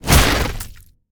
Sfx_creature_rockpuncher_flinchbig_01.ogg